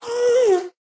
sounds / mob / ghast / moan3.ogg
moan3.ogg